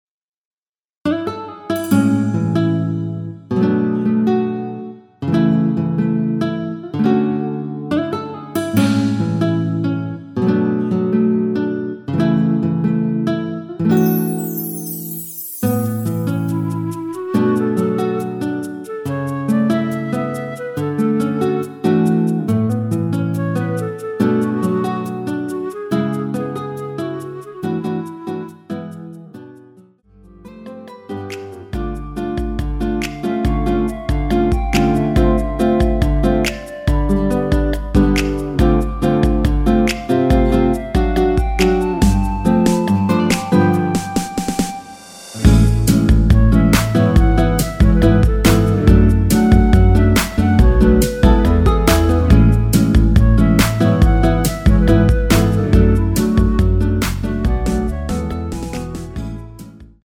원키에서(-1)내린 멜로디 포함된 MR 입니다.(미리듣기 참조)
◈ 곡명 옆 (-1)은 반음 내림, (+1)은 반음 올림 입니다.
멜로디 MR이라고 합니다.
앞부분30초, 뒷부분30초씩 편집해서 올려 드리고 있습니다.